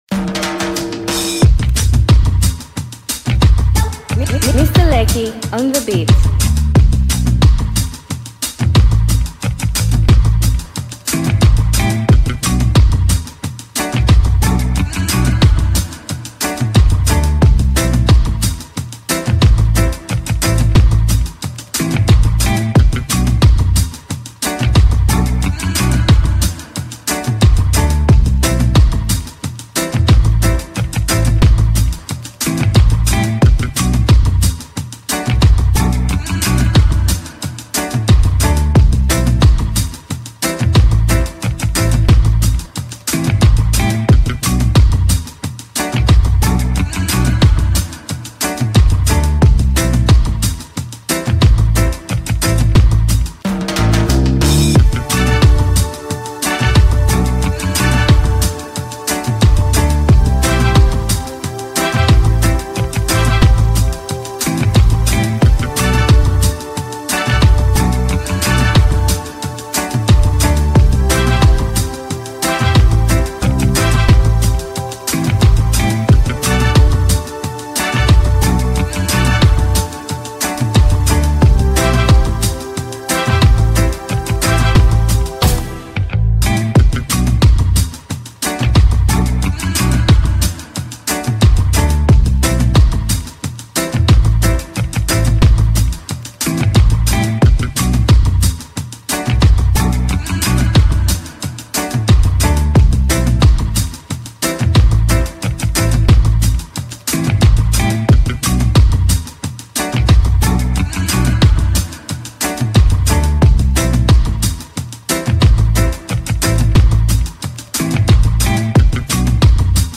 Download free beat